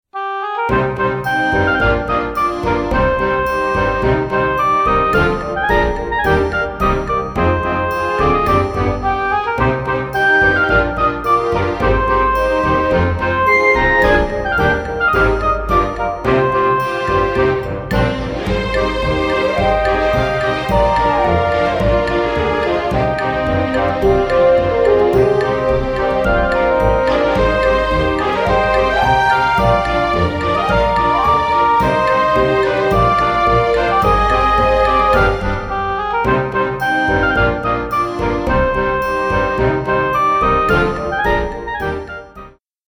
森・田舎・素朴